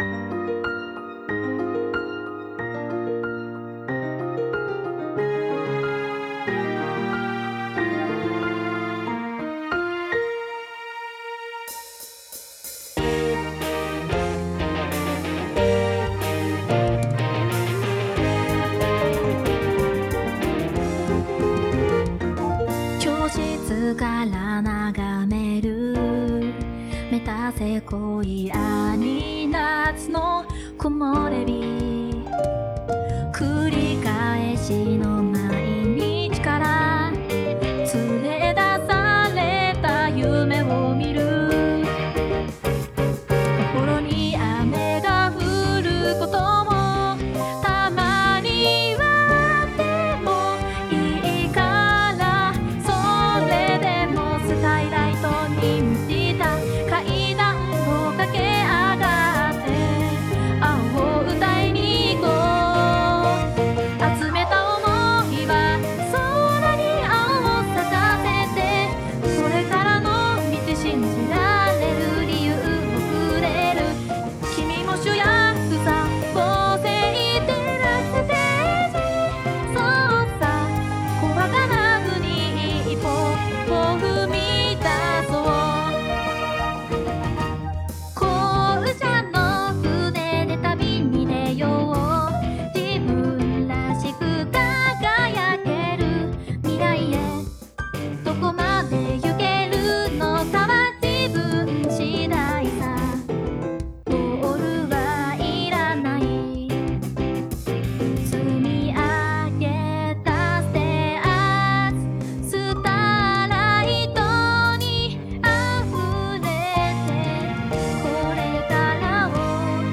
この曲は、実行委員の発案から生まれ、軽音楽部の全面的な協力により完成しました。